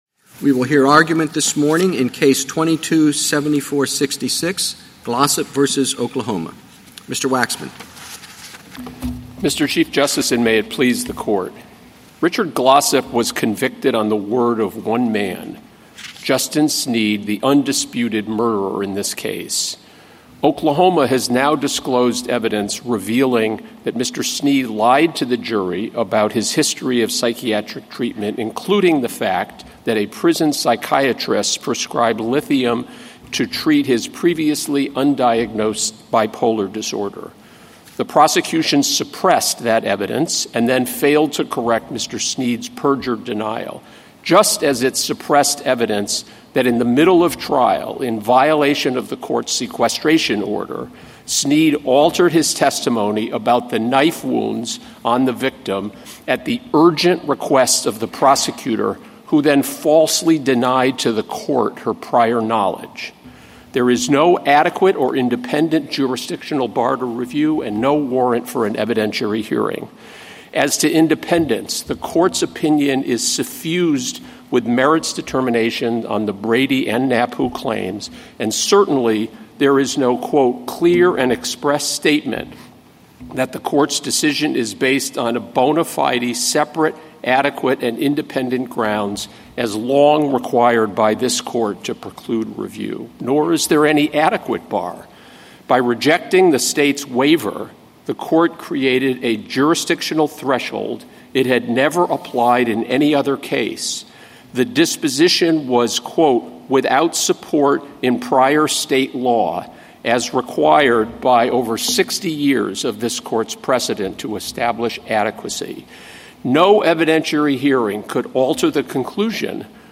Oral Argument - Audio